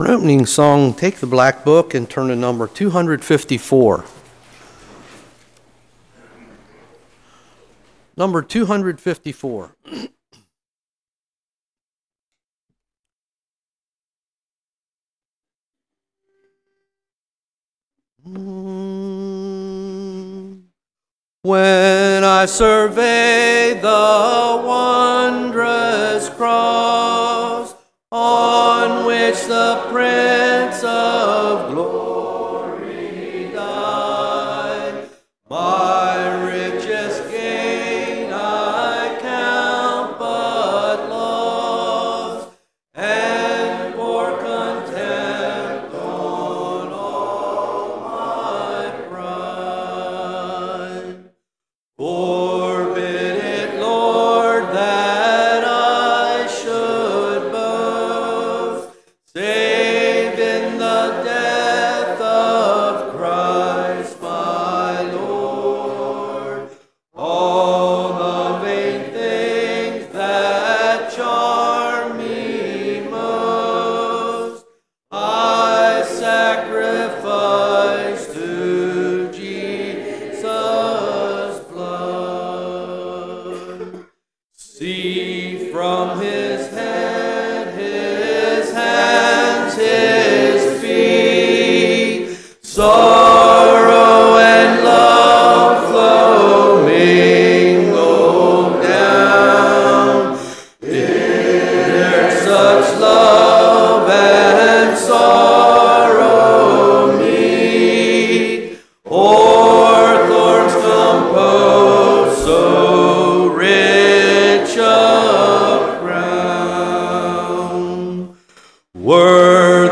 Hymn Sing